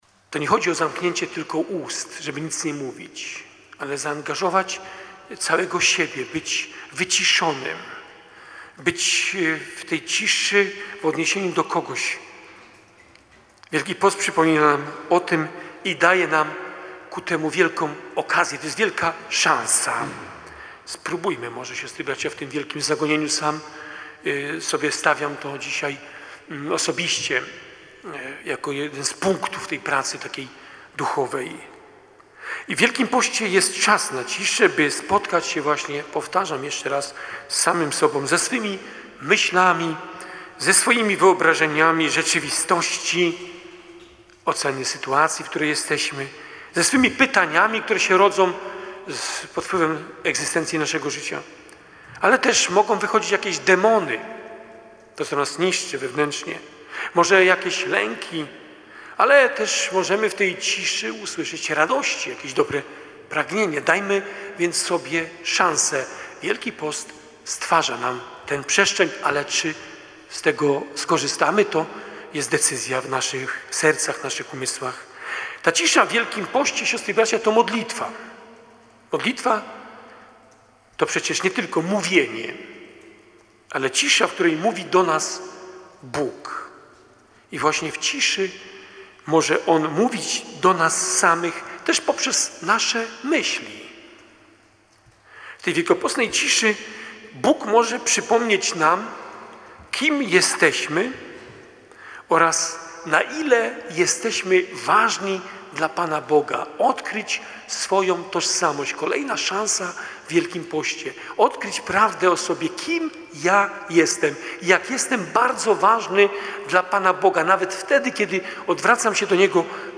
Bp Krzysztof Włodarczyk przewodniczył Mszy św. w Środę Popielcową w koszalińskiej katedrze.